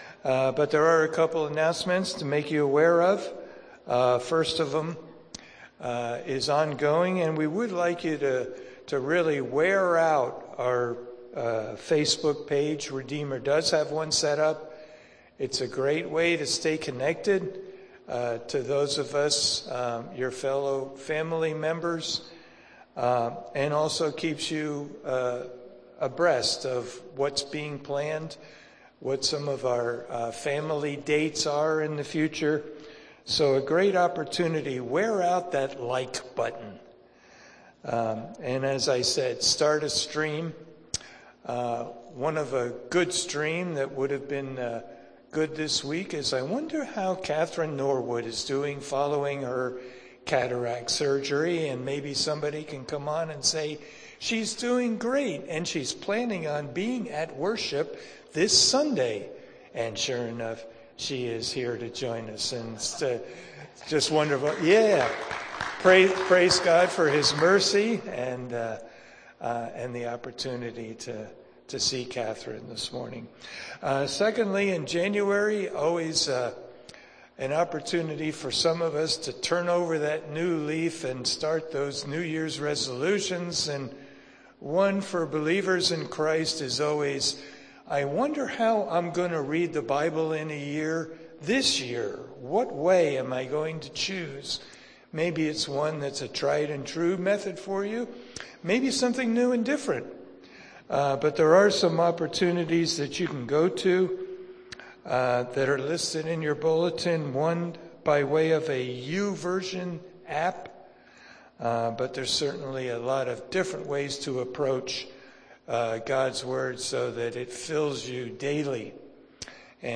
Sunday Morning Worship